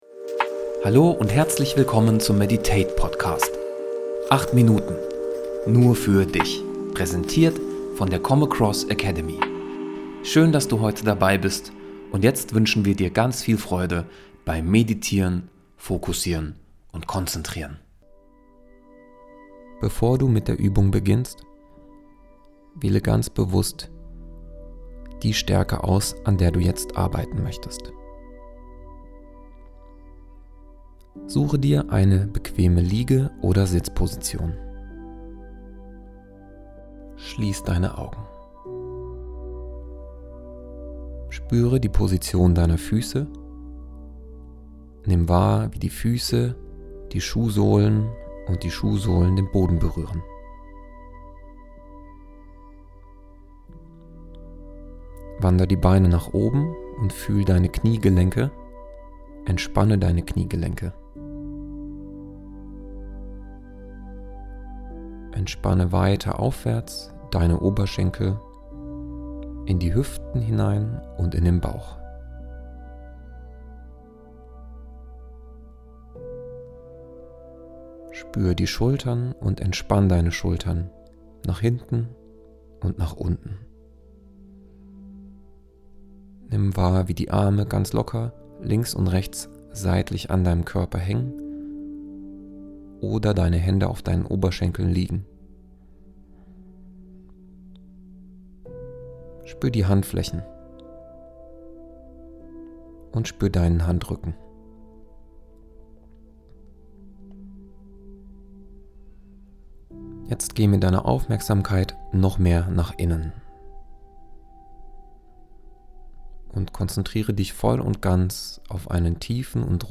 Stärkenvisualisierung [Meditation]